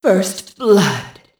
Index of /server/sound/foot/quake/female